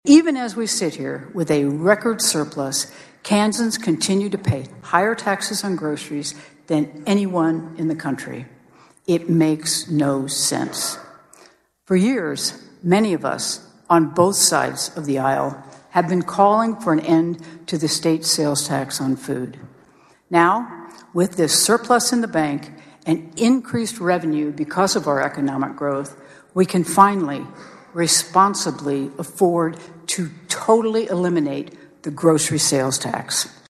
Citing her approach of “fiscal sanity” during her first three years in office, Kansas Governor Laura Kelly outlined several policy goals she says are a direct result of prudent budget management as part of her State of the State address Tuesday night.